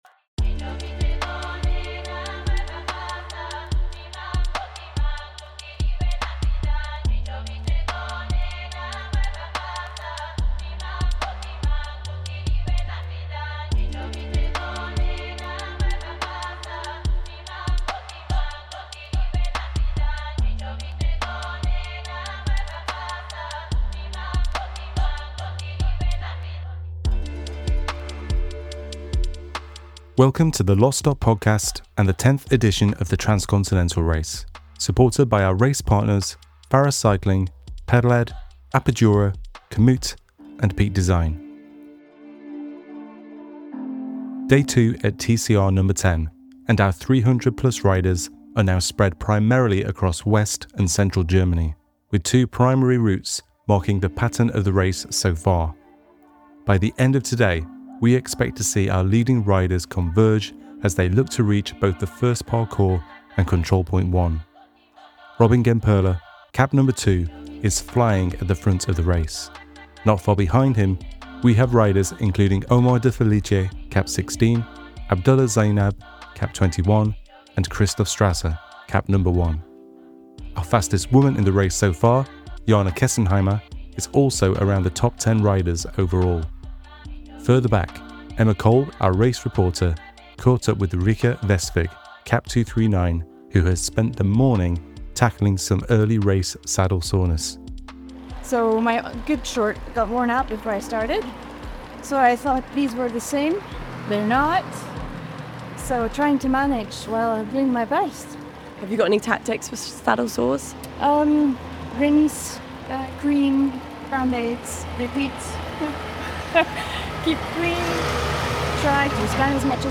This episode captures the hours ahead of the first arrival at CP1, catching up with the front of the Race as they ascend the Katschberg. We also hear from a recipient of our Low Carbon Travel Grant as well as one of our Bursary Riders. With a second major route choice imminent, the Race is once again changing shape as riders weigh up the consequences of decisions, past and future.